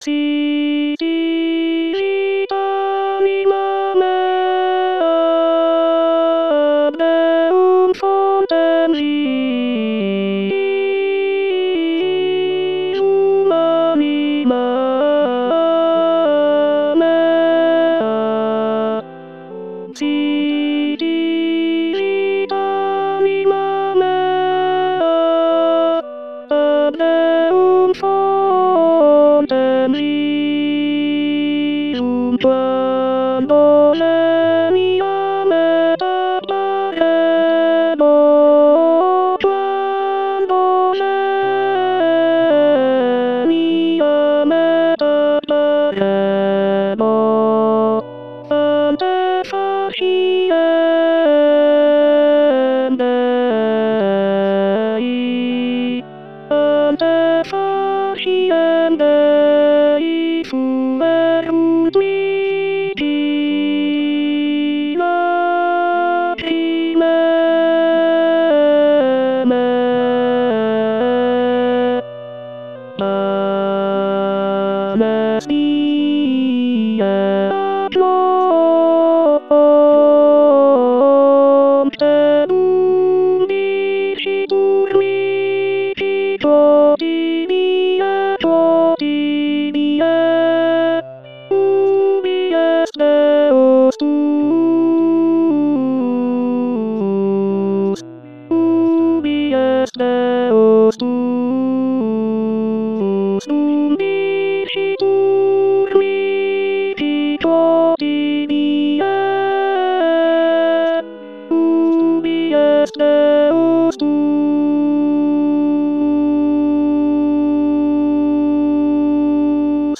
Palestrina_Sicut cervus [guida audio Contralto]